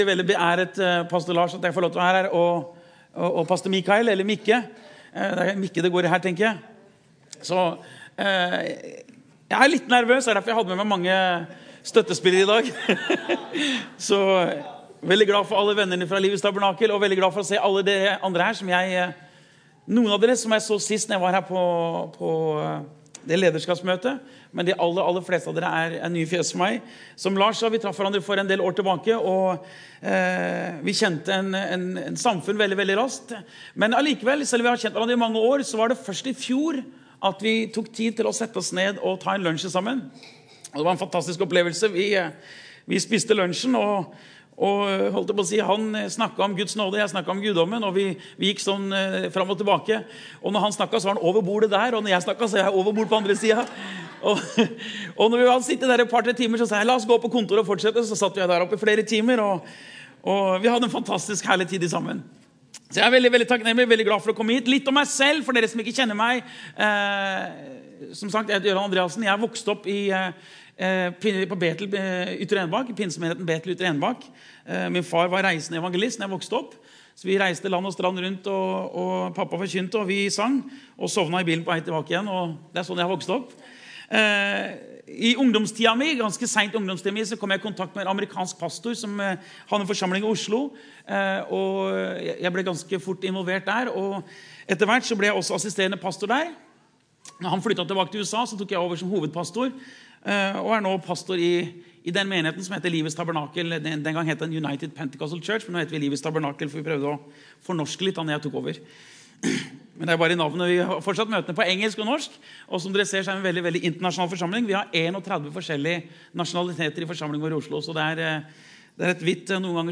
Gudstjenester